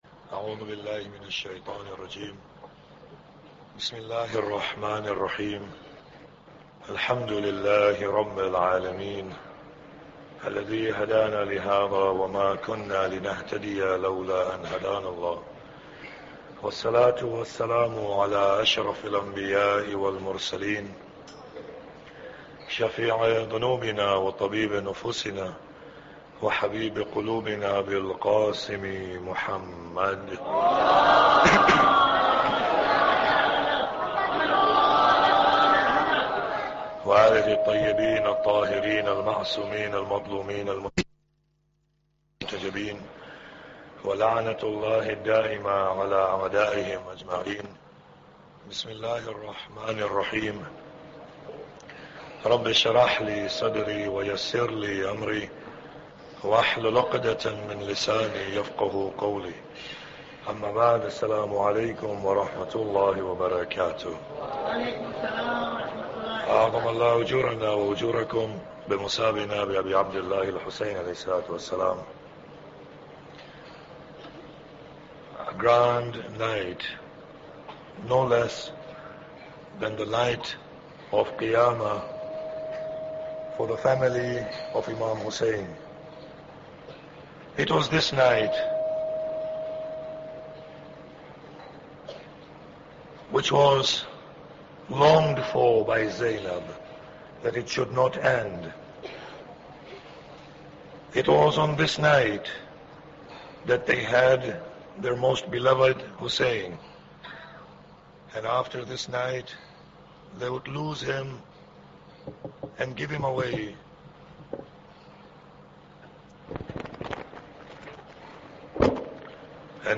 Muharram Lecture 10